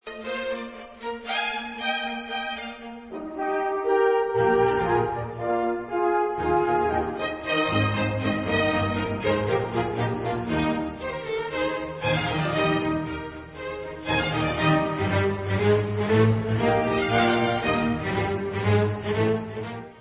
Koncert pro dva lesní rohy, orchestr a cembalo Es dur